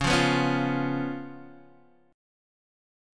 C#7 chord